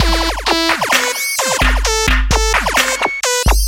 嘻哈音乐鼓点
描述：快乐
标签： 130 bpm Hip Hop Loops Drum Loops 634.98 KB wav Key : Unknown
声道立体声